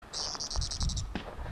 Scientific name: Leptasthenura aegithaloides pallida
English Name: Plain-mantled Tit-Spinetail
Life Stage: Adult
Location or protected area: Ea. La Clara, 10km al norte de Isla Escondida
Condition: Wild
Certainty: Observed, Recorded vocal